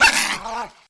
Index of /App/sound/monster/misterious_diseased_dog
attack_1.wav